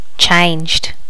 Additional sounds, some clean up but still need to do click removal on the majority.